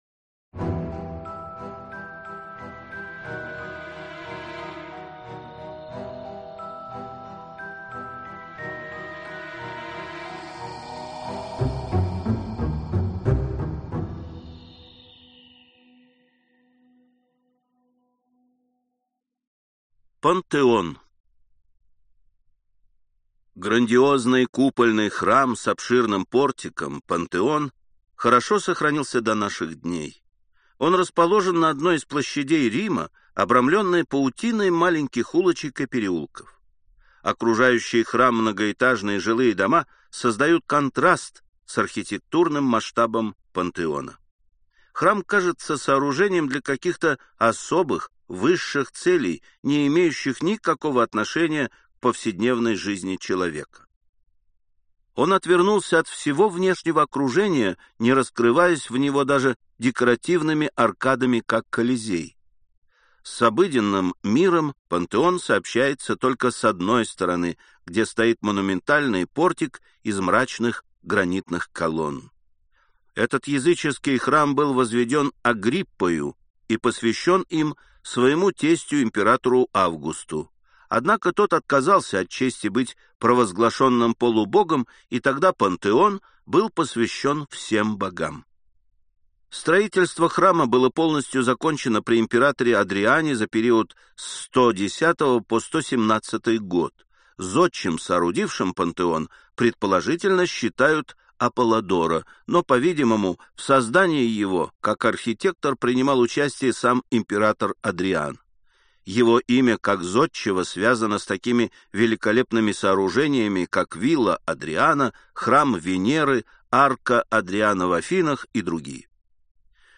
Аудиокнига Чудеса света | Библиотека аудиокниг